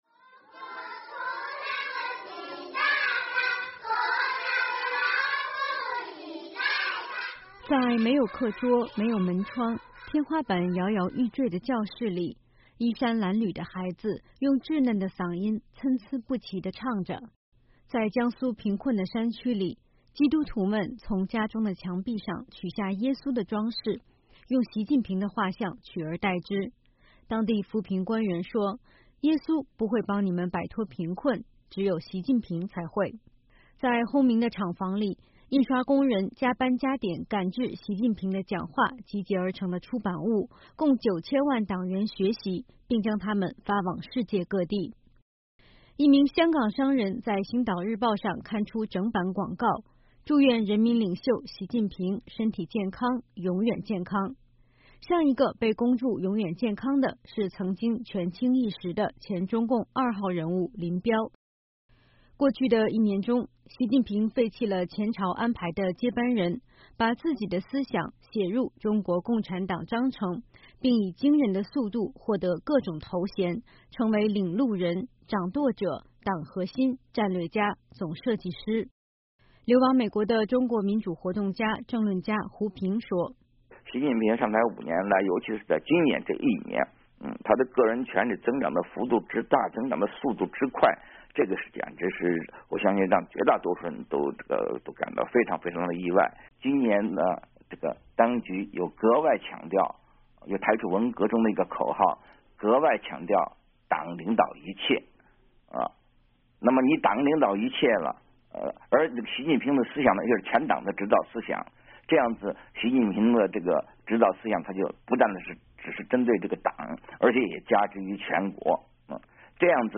年终报道